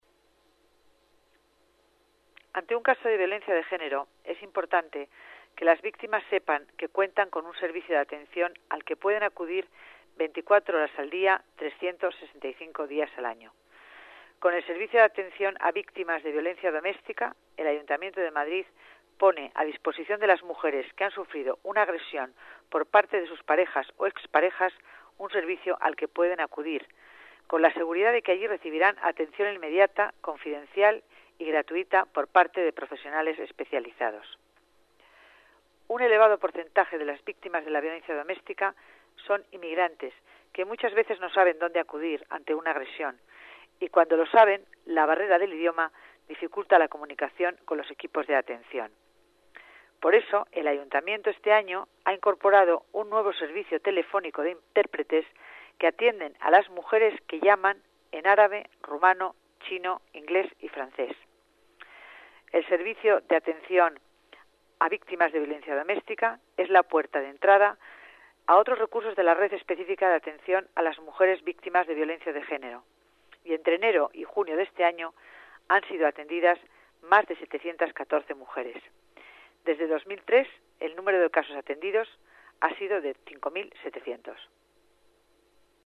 Nueva ventana:Declaraciones de Concepción Dancausa, delegada de Familia y Servicios Sociales